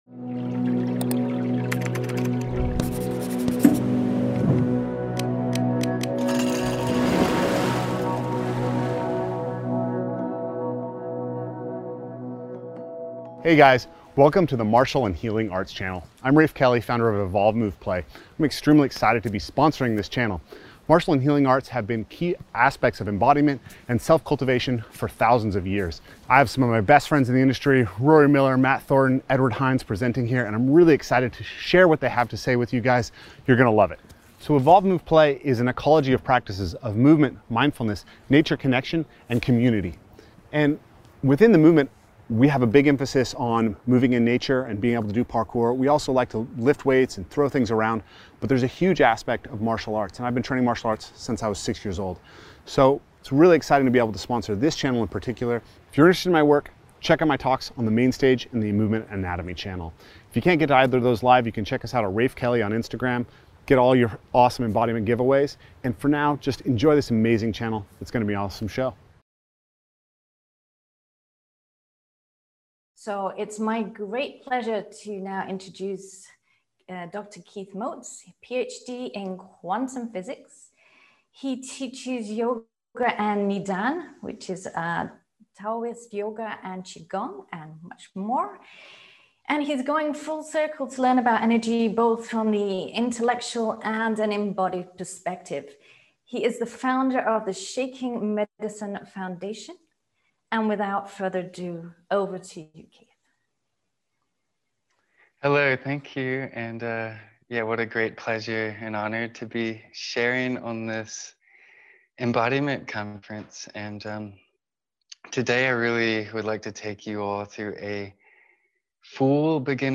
In this workshop we will gently warm up, ground and center ourselves with simple Qi Gong techniques and then explore the tremor response in various shapes as the focus of this journey.